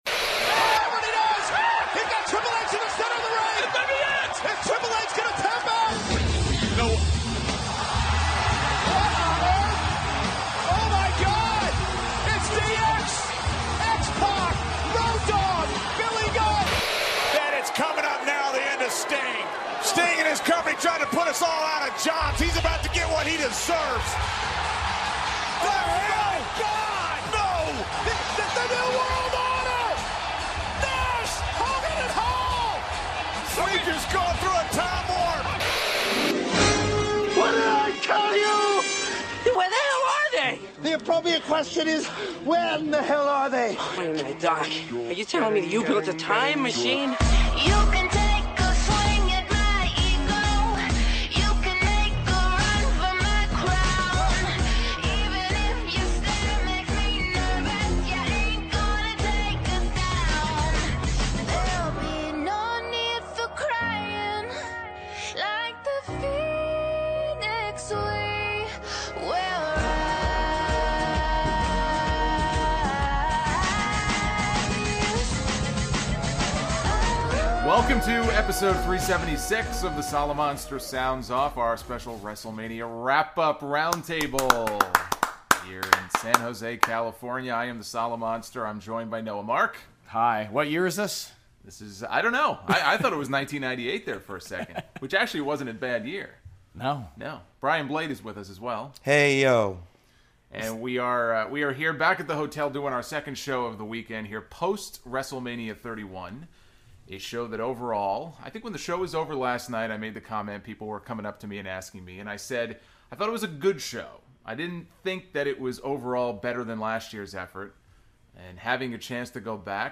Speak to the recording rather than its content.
Recorded on location in San Jose, California.